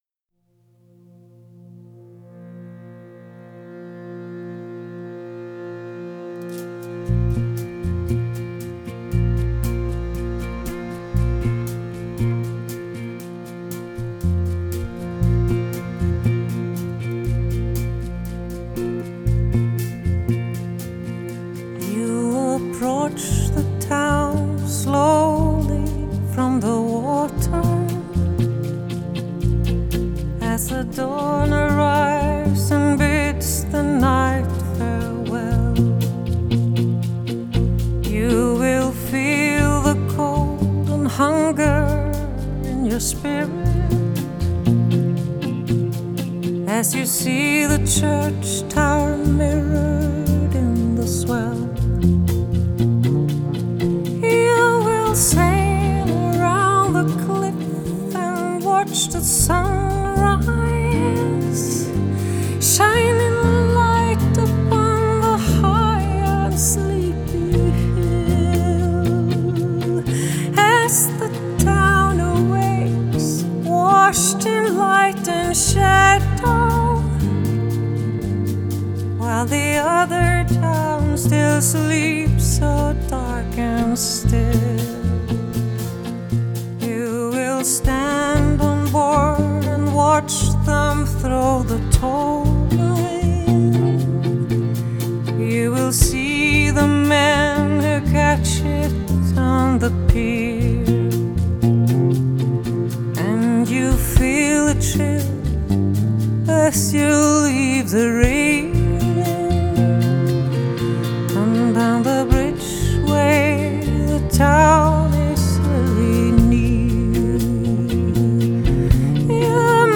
Genre: Pop